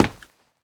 scpcb-godot/SFX/Step/Run1.ogg at 59a9ac02fec0c26d3f2b1135b8e2b2ea652d5ff6